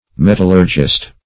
Metallurgist \Met"al*lur`gist\, n. [Cf. F. m['e]tallurgiste.]